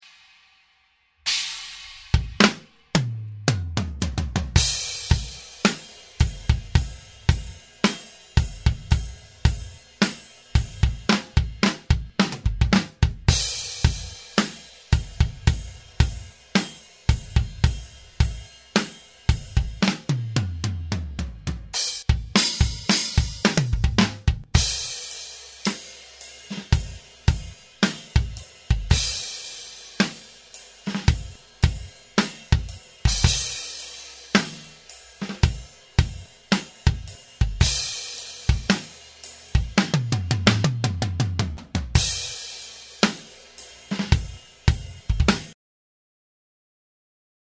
Dry drums with Snare centered Two samples for you here. Snare 1 mix = just the one close mic right on the snare Snare 2 mix = just the snare that bled into the Tom1 mic. Just raw drums with no processing at all on them. No trickery FYI, there is also dual OH mics panned hard right and Left and 1 stereo room mic. Attachments Drums Snare 1.mp3 Drums Snare 1.mp3 741.8 KB · Views: 61